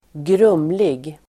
Uttal: [²gr'um:lig]